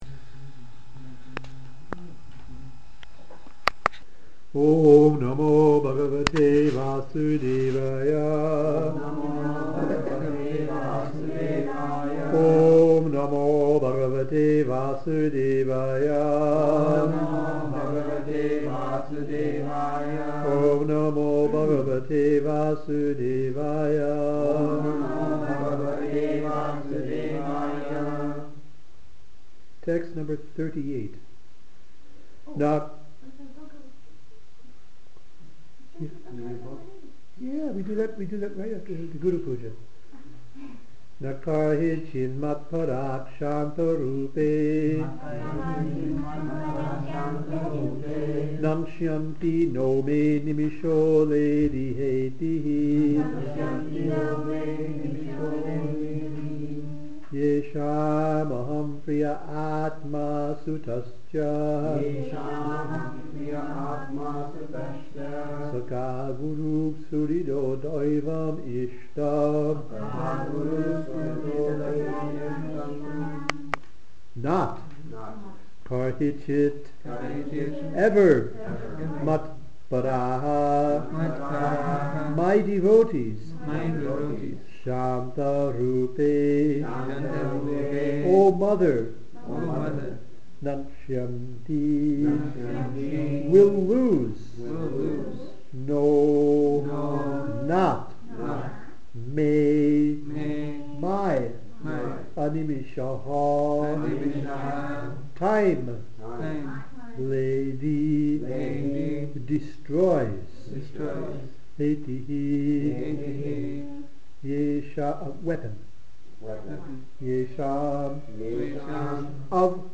Srimad Bhagavatam Audio Lecture